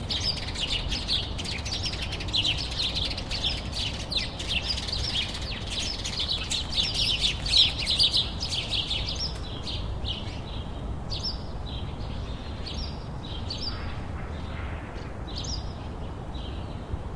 现场录音»早上的鸟类
黑鸟，Tomtit，麻雀，野鸽，乌鸦，喜鹊。
采用Swissonic MDR2和假头麦克风套装录制。
Tag: 乌鸦 唱歌 喜鹊 野生 上午 鸽子 黑鹂 麻雀 Tomtit